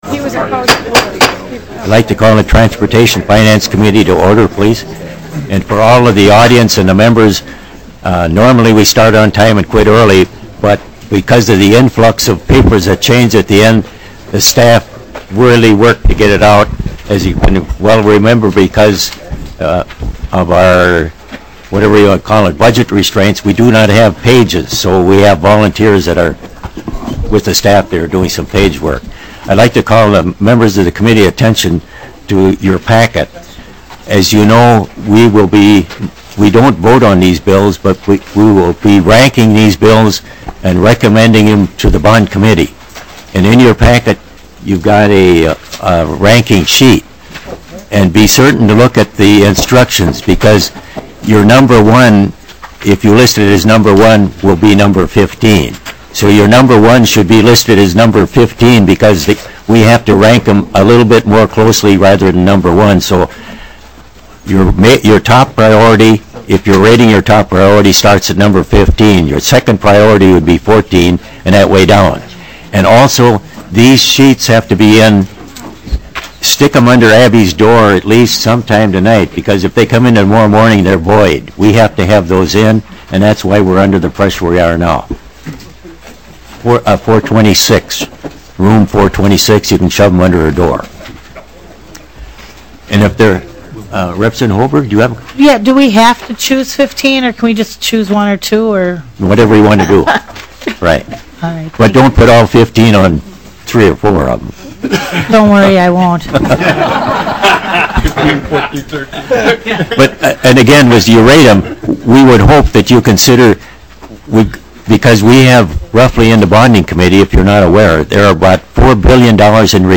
10:10 - Gavel and introductory remarks. 13:15 - HF2544 (Norton) Southern Rail Corridor project funding provided, bonds issued, and money appropriated.